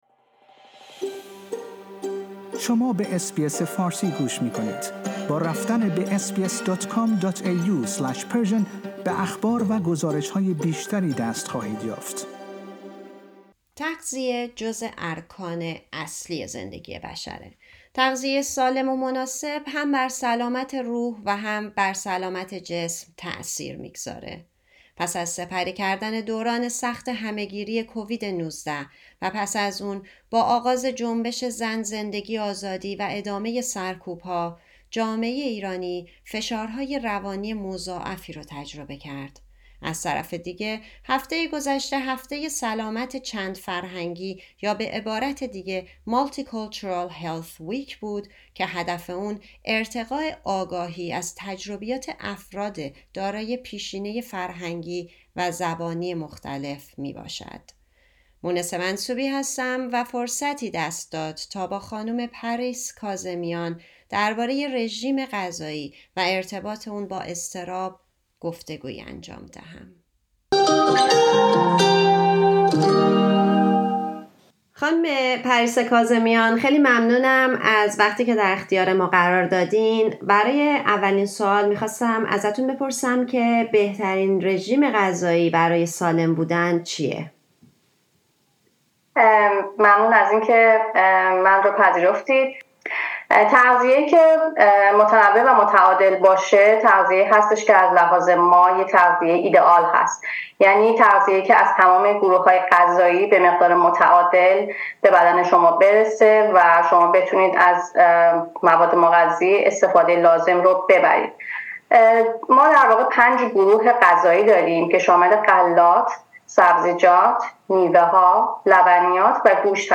درباره رژیم غذایی و ارتباط آن با اضطراب گفتگویی انجام دهیم.